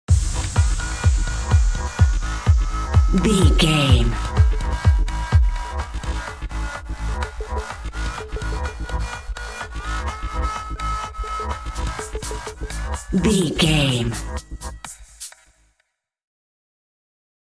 Modern Chart Pop Electronic Stinger.
Aeolian/Minor
groovy
uplifting
driving
energetic
repetitive
synthesiser
drum machine
house
electro dance
techno
synth leads
synth bass
upbeat